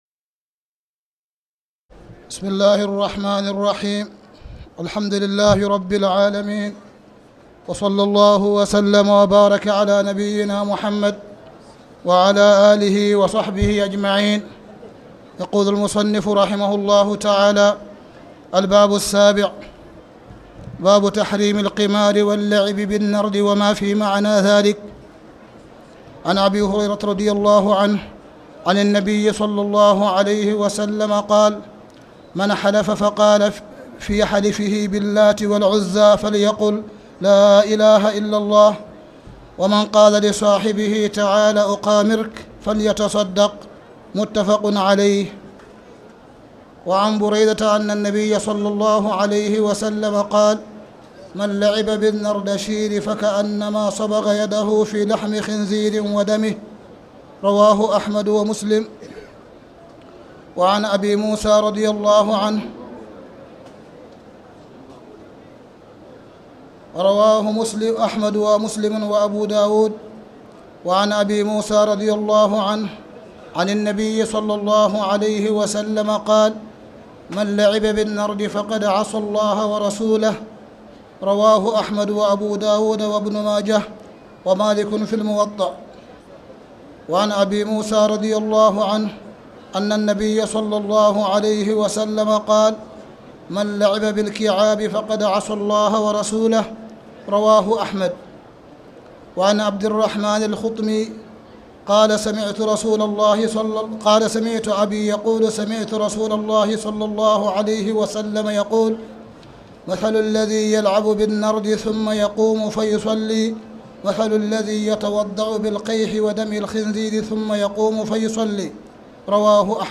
تاريخ النشر ١٣ رمضان ١٤٣٨ هـ المكان: المسجد الحرام الشيخ: معالي الشيخ أ.د. صالح بن عبدالله بن حميد معالي الشيخ أ.د. صالح بن عبدالله بن حميد باب تحريم القمار ومافي معنى ذلك